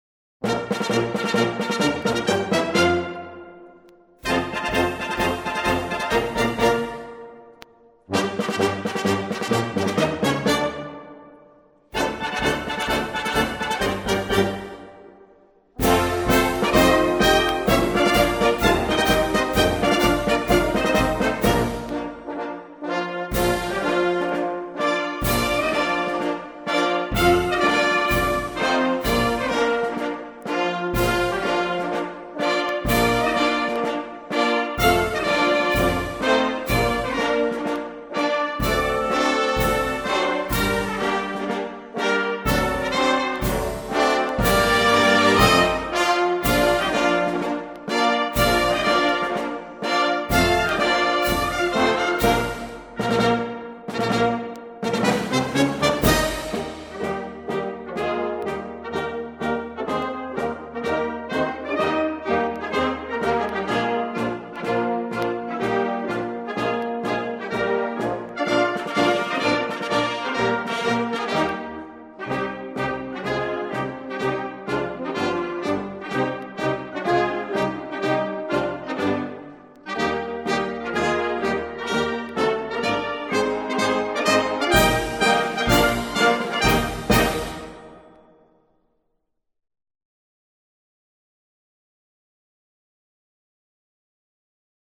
hymne_national_italie_fratelli_d_italia_musique.mp3